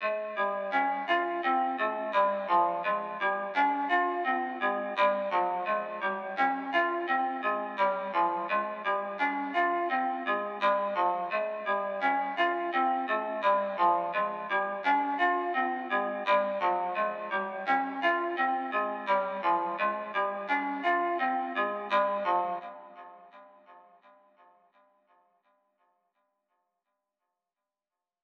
EN - Jungle II (85 BPM).wav